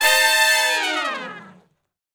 014 Long Falloff (F) har.wav